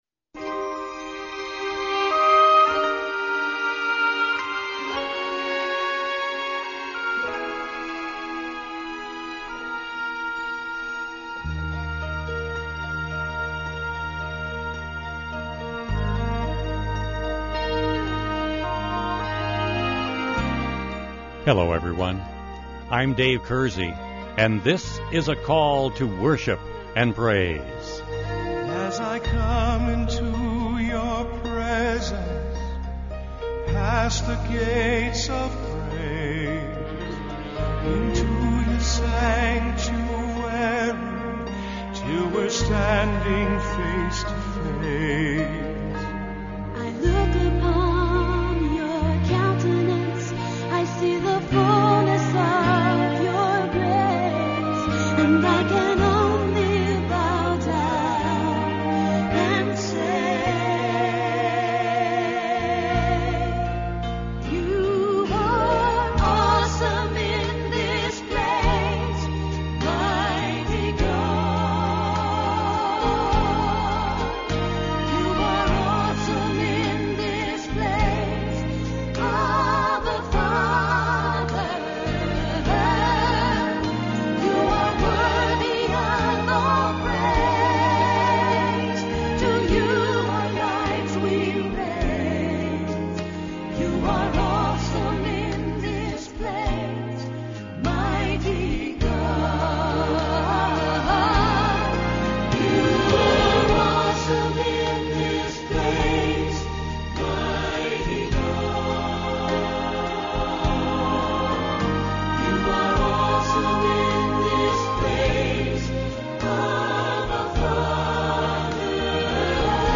This week’s Devotional Topic This week on Call To Worship we will be singing and sharing about the joy that is the Christian’s when they “Trust and Obey” their Lord.